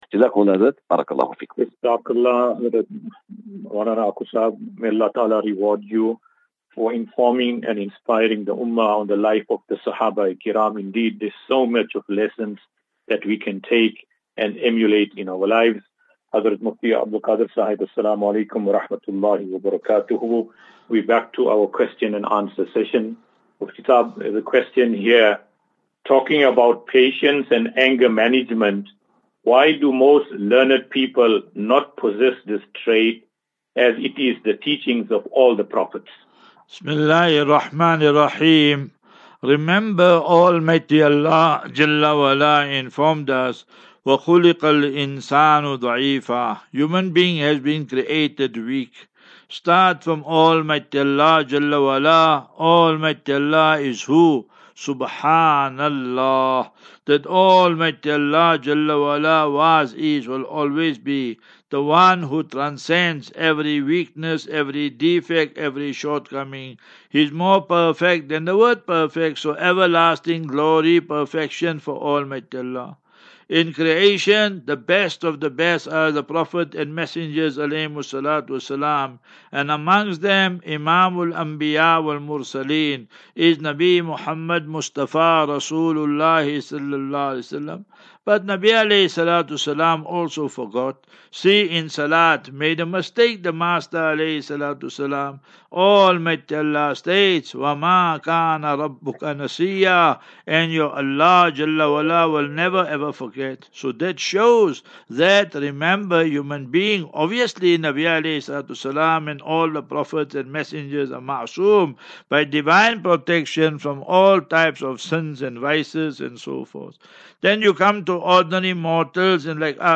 View Promo Continue Install As Safinatu Ilal Jannah Naseeha and Q and A 25 Mar 25 March 2024.